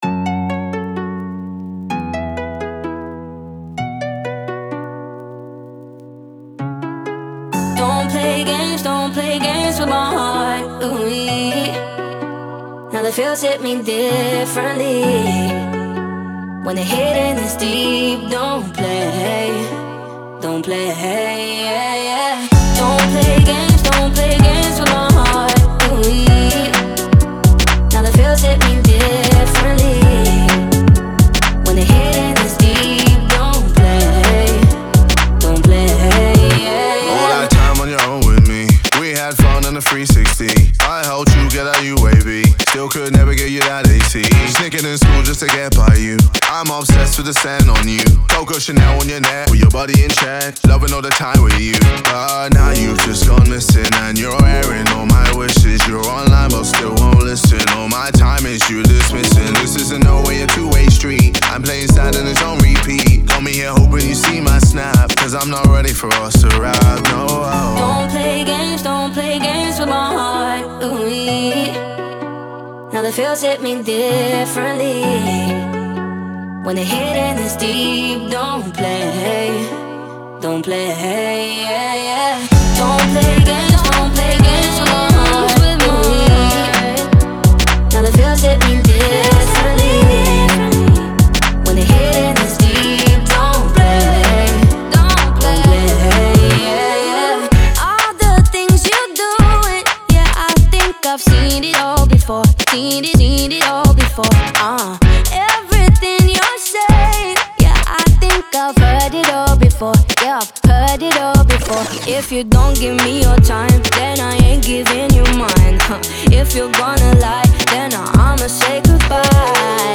это энергичная поп-музыка с элементами хип-хопа